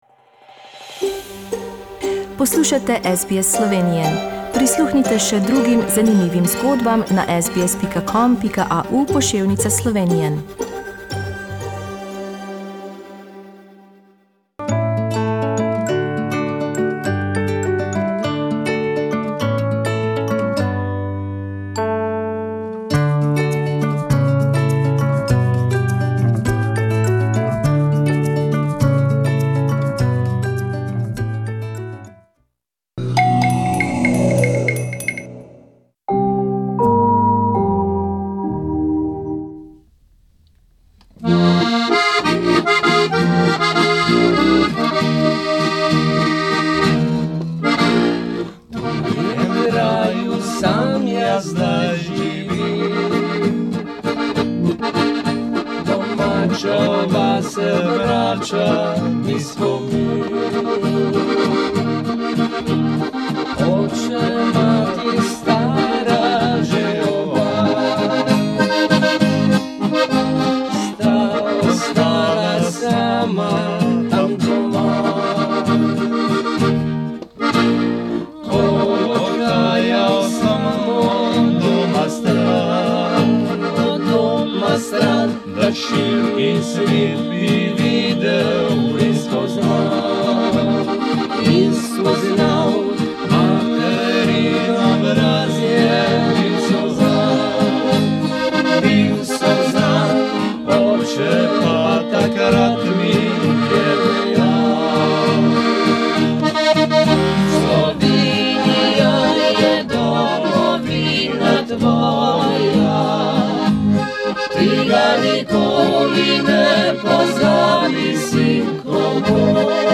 Družina Rus live in our Melbourne studio on Saturday 28 December Source: SBS Slovenian